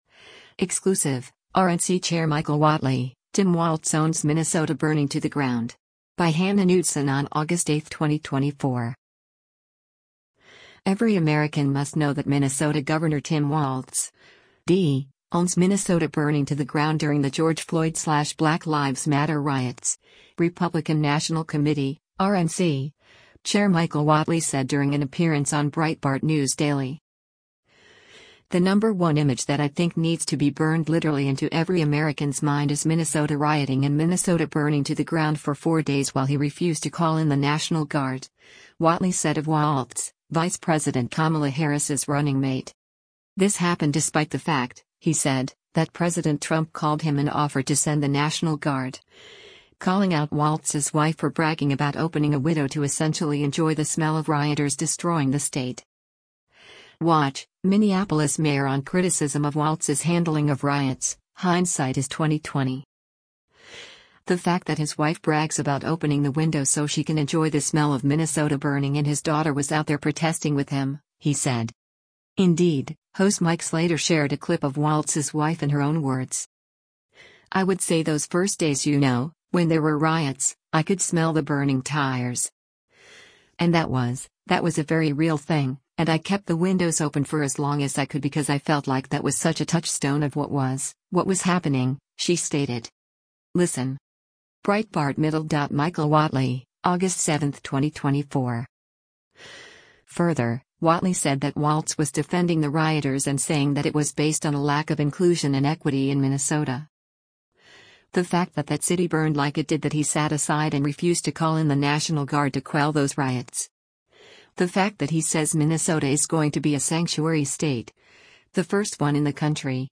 Every American must know that Minnesota Gov. Tim Walz (D) owns Minnesota burning to the ground during the George Floyd/Black Lives Matter riots, Republican National Committee (RNC) Chair Michael Whatley said during an appearance on Breitbart News Daily.